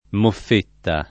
moffetta [ moff % tta ]